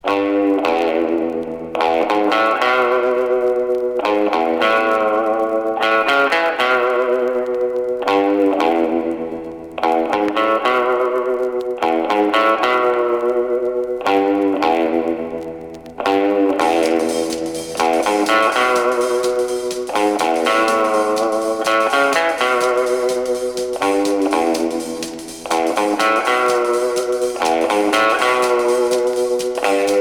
R'n'r